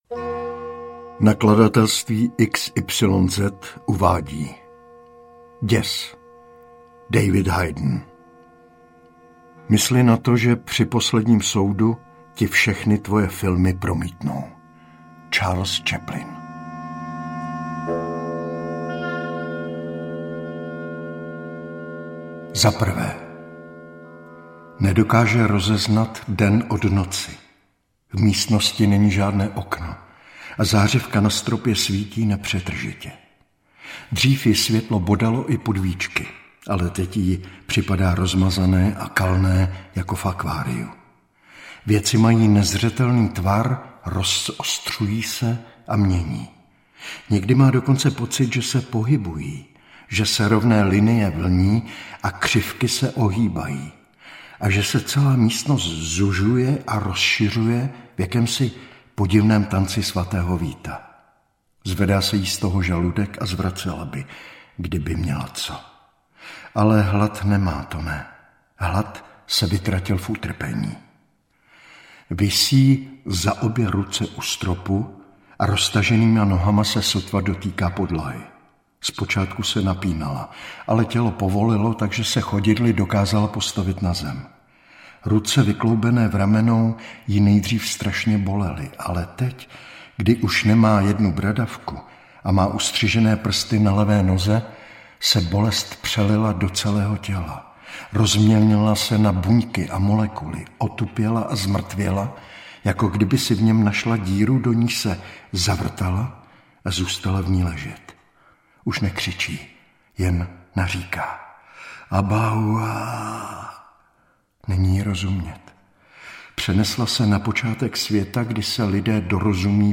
Děs audiokniha
Ukázka z knihy